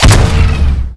fire_plasma5.wav